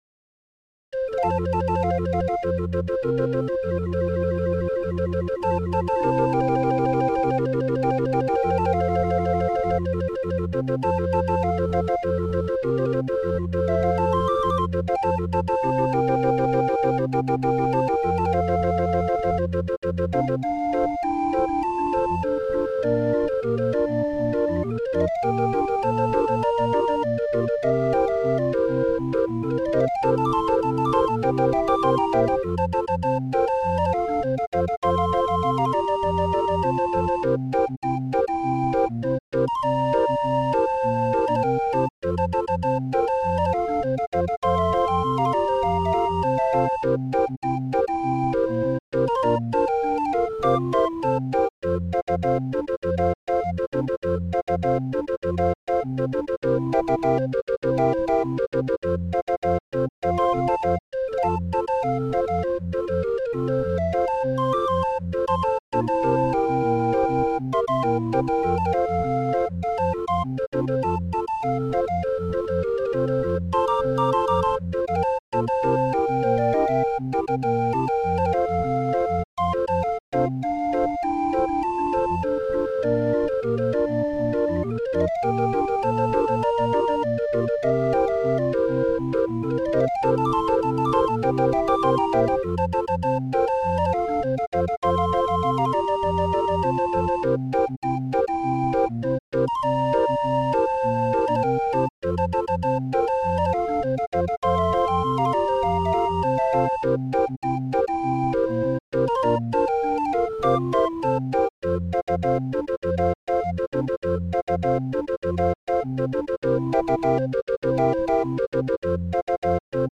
Muziekrol voor Raffin 20-er